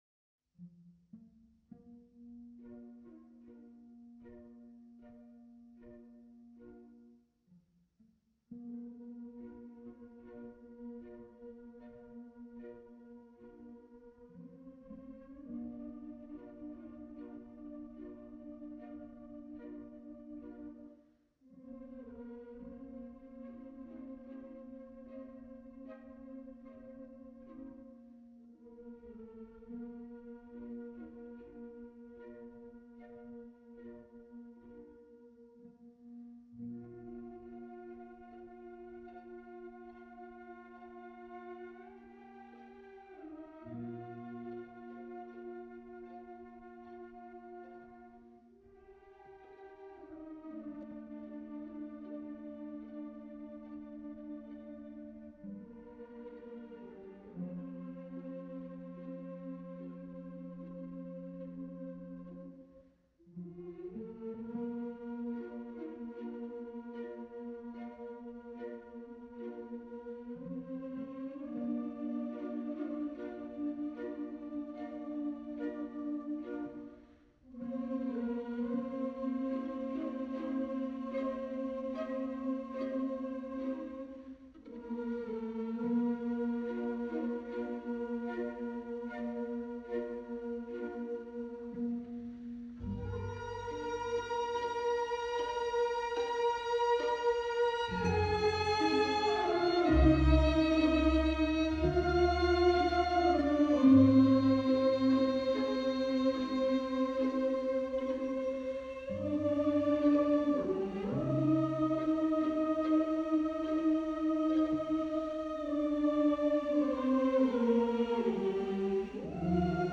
What better “Butterfly” music to grace this post than the Humming Chorus from Madame Butterfly, don’t you agree?